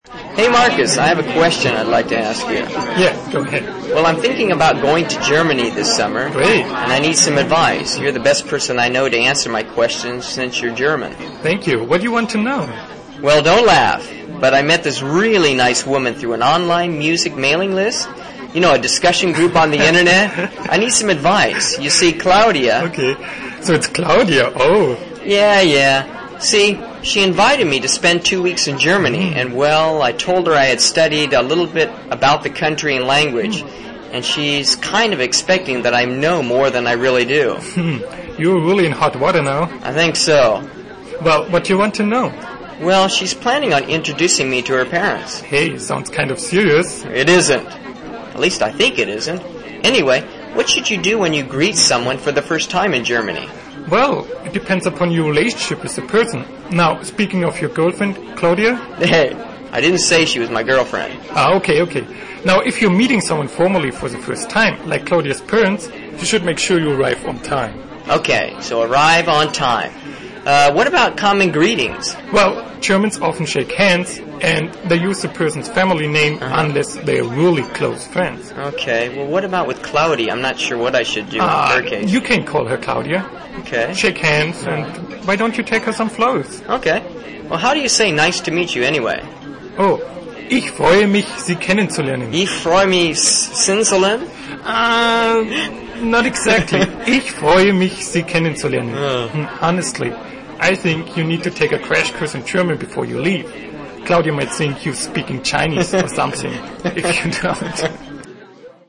【听英文对话做选择】假期计划 听力文件下载—在线英语听力室